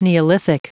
Prononciation du mot neolithic en anglais (fichier audio)
Prononciation du mot : neolithic